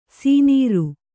♪ sīnīru